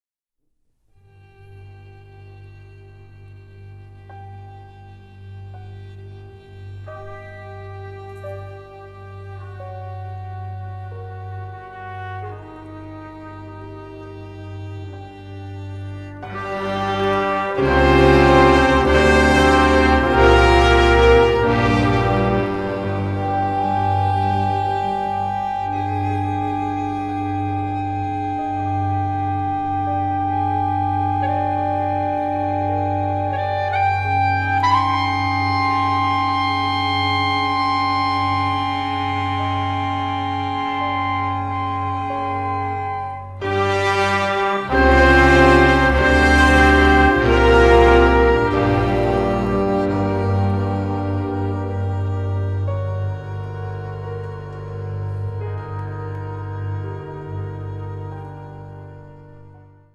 cymbalon